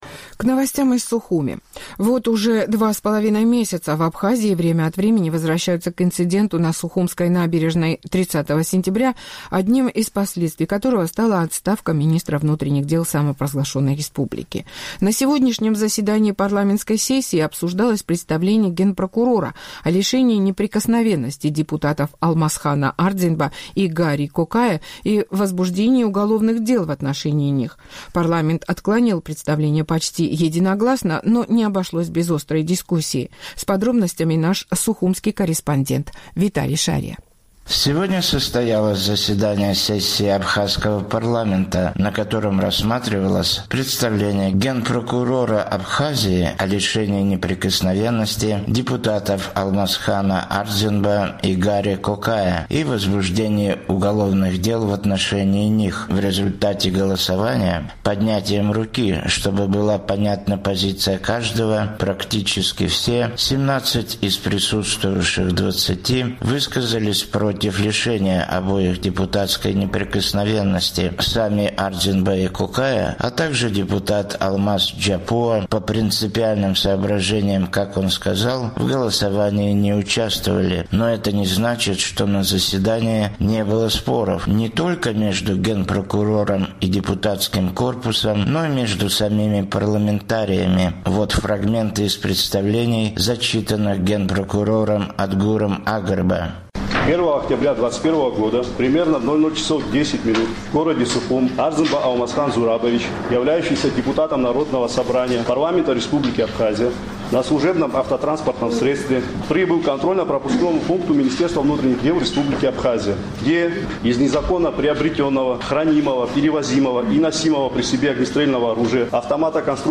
Сегодня состоялось заседание сессии абхазского парламента, на котором рассматривалось представление генпрокурора Абхазии о лишении неприкосновенности депутатов Алмасхана Ардзинба и Гарри Кокая и возбуждении уголовных дел в отношении них.